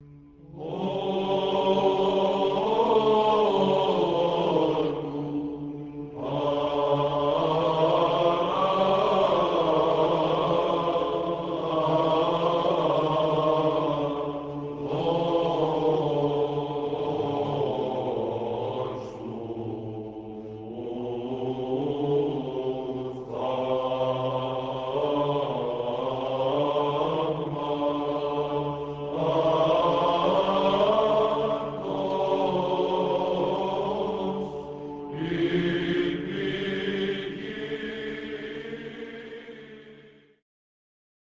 2. Prosomion of the Dormition, First mode